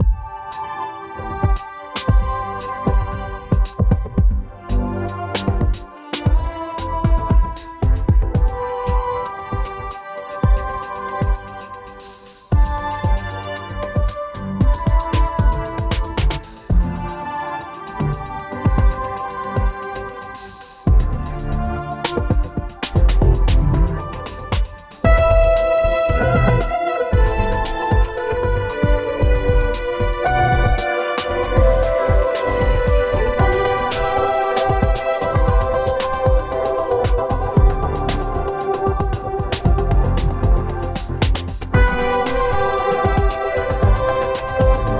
Dynamic electro beat